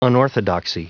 Prononciation du mot unorthodoxy en anglais (fichier audio)